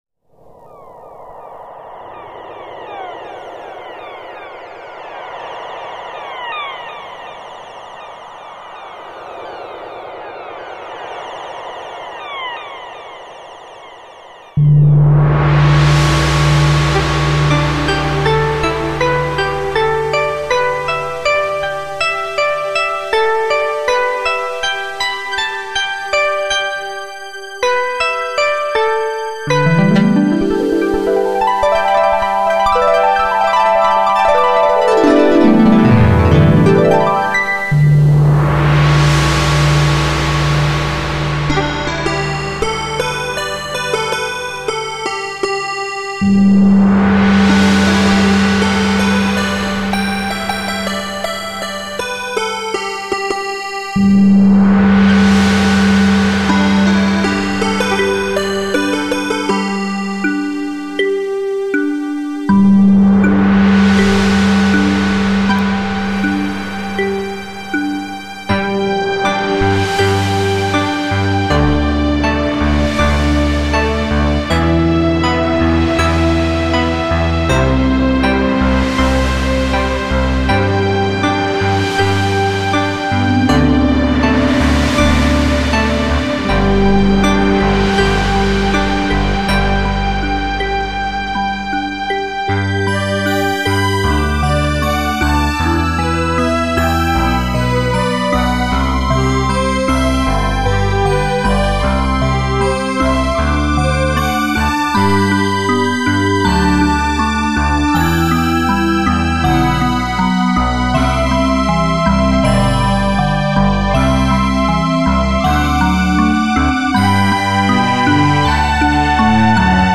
All sounds must be produced by one or more ESQ1 and/or SQ80.